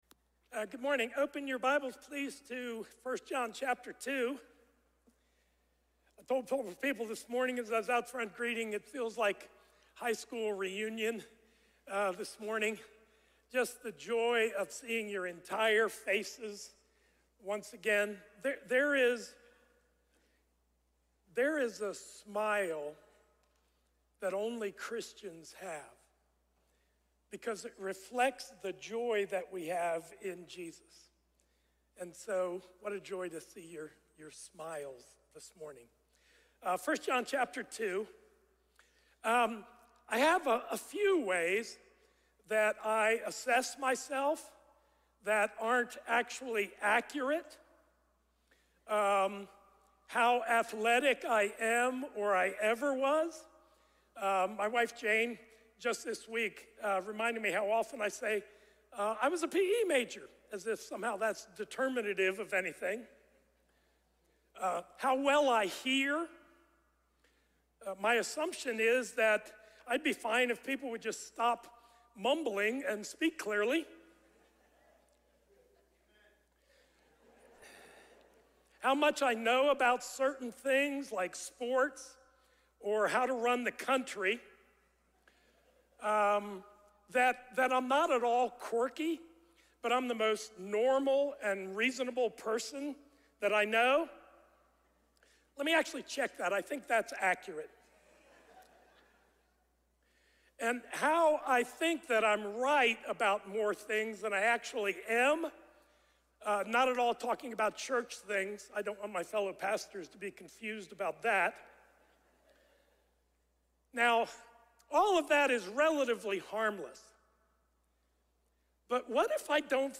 A message from the series "Judges: {Un} Faithful."